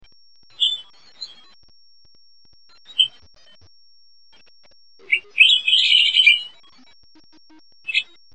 b. Shrill,
bickering calls (P).